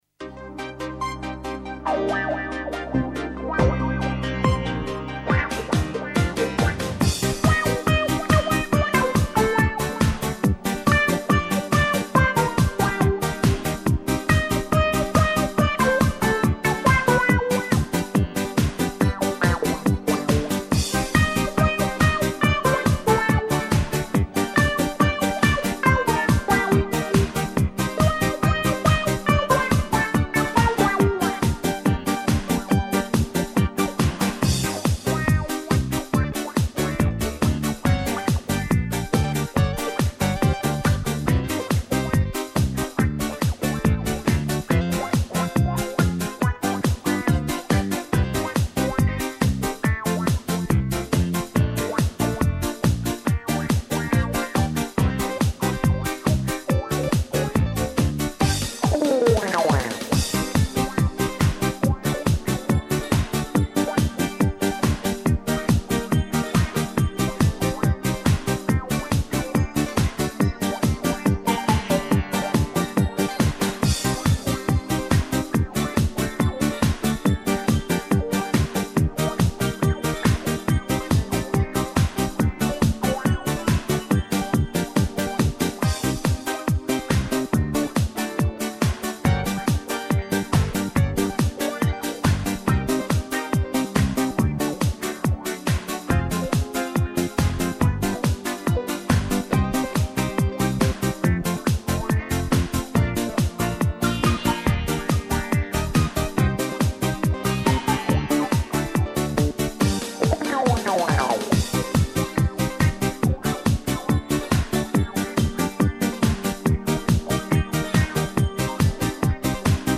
минусовка версия 214631